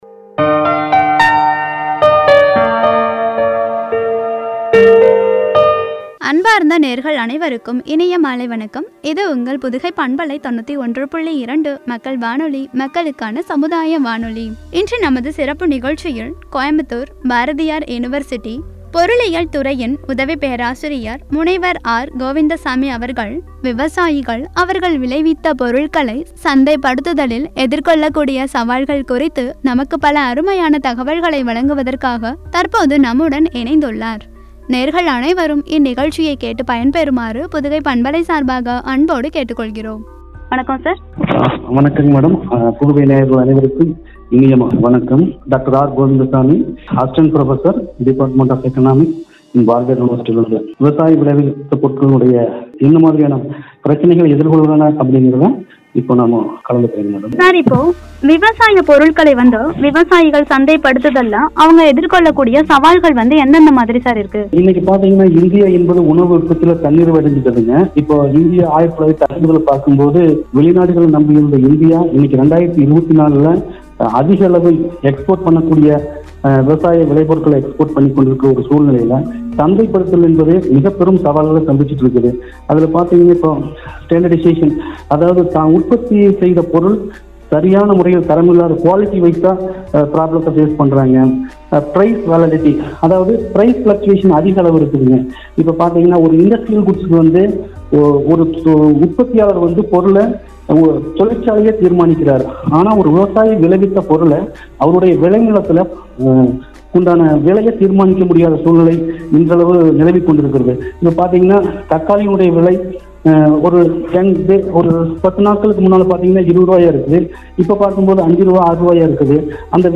சவால்களும்” என்ற தலைப்பில் வழங்கிய உரையாடல்